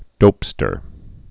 (dōpstər)